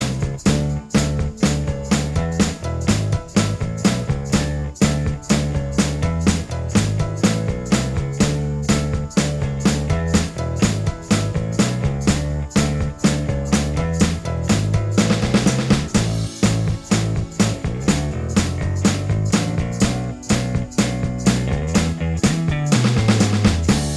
Minus All Guitars Pop (1980s) 4:38 Buy £1.50